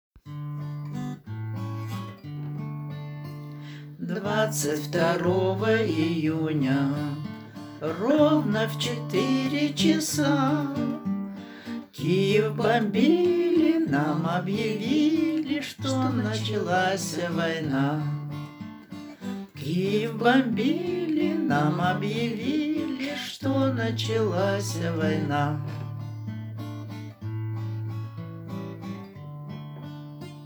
Lied über den Kriegsbeginn am 22. Juni 1941, Gesang und Instrument